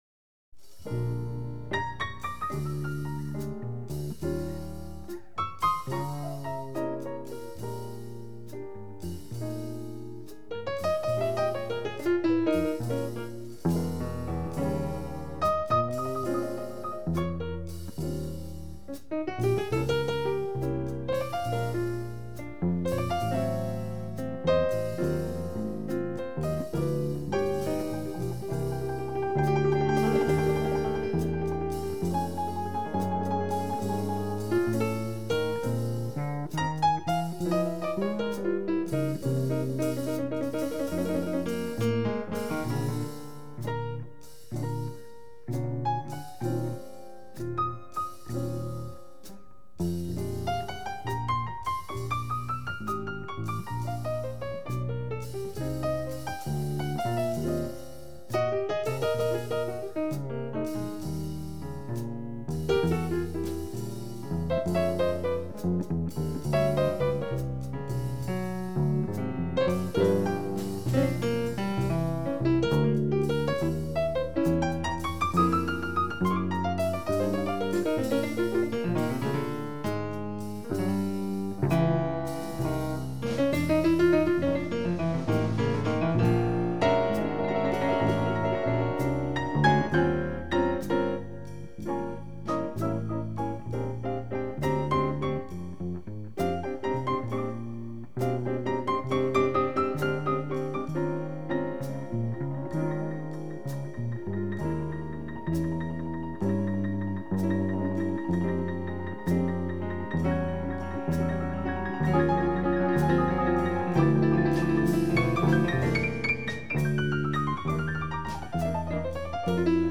bass
drums